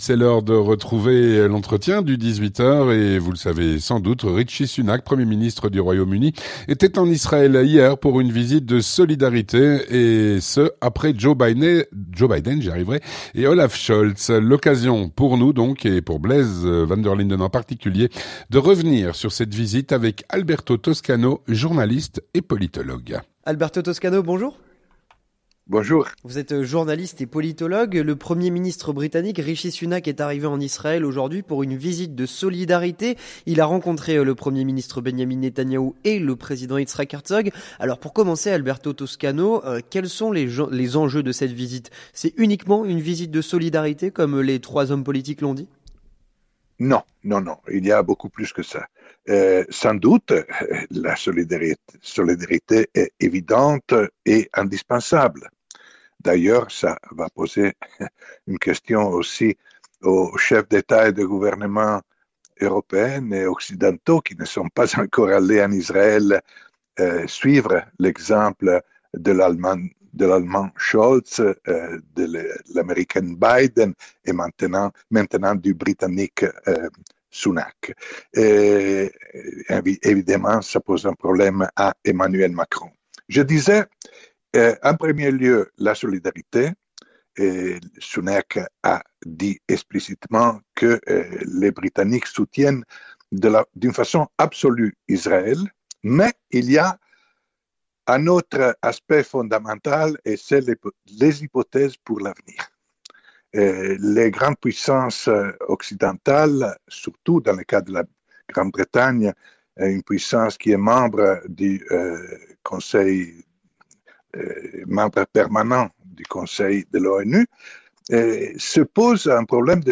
journaliste et politologue